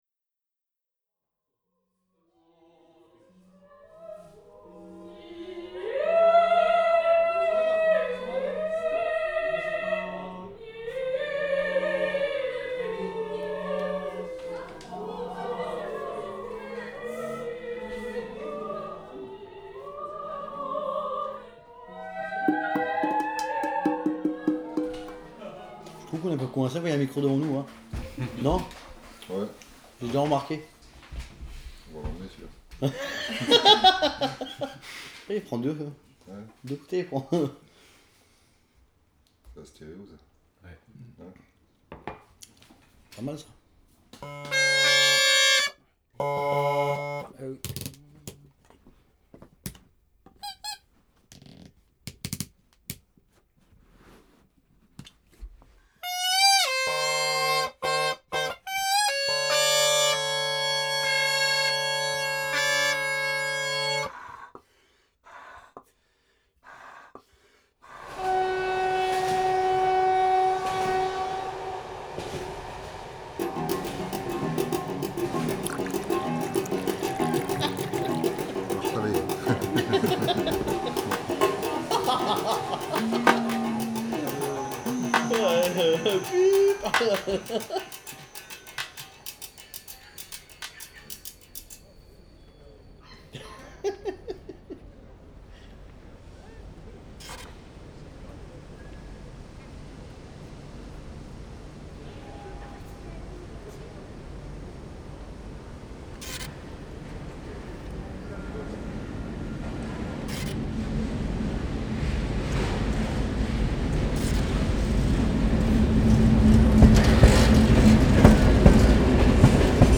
Documentaire sonore / Stéréo
[Enregistré sur Nagra V en 24b/48kHz - Microphones Schoeps]